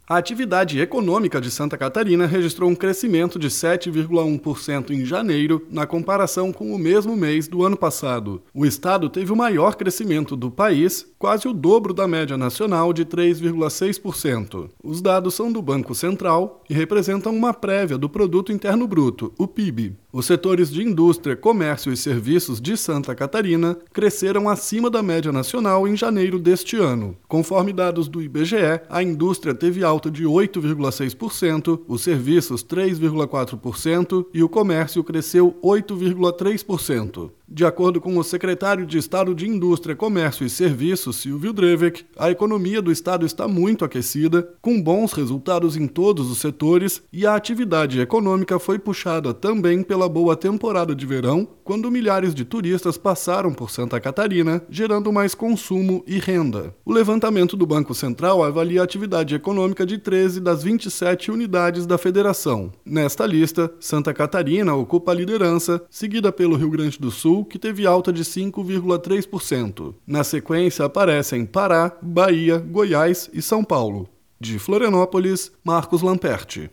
BOLETIM – Atividade econômica de Santa Catarina cresce 7,1% em janeiro, maior alta do país